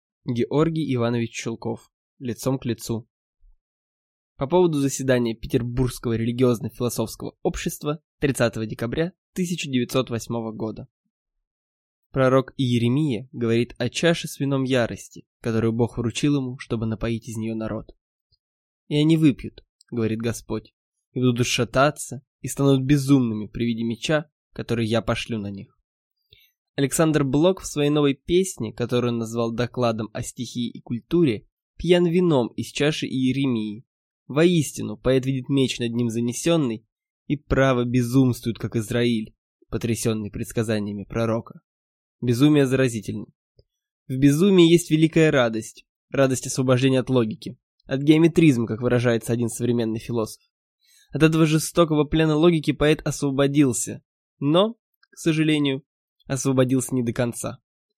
Аудиокнига Лицом к лицу | Библиотека аудиокниг